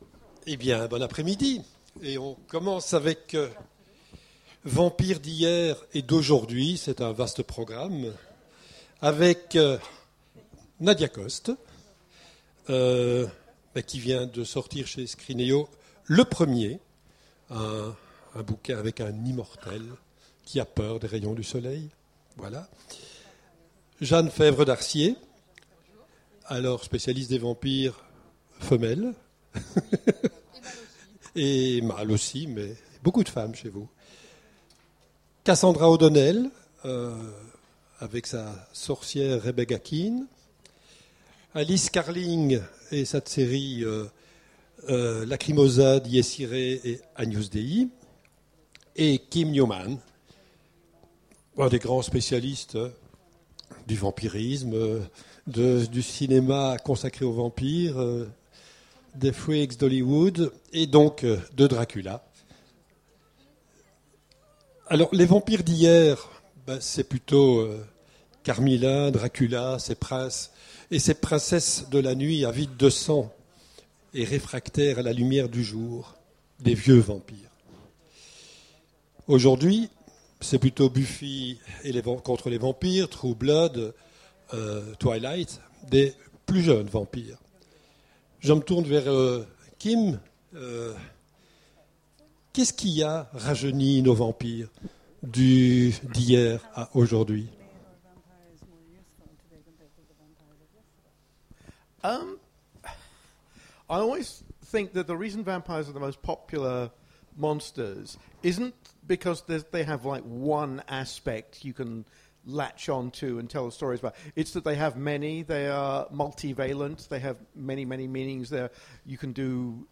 Imaginales 2015 : Conférence Vampires d'hier et d'aujourd'hui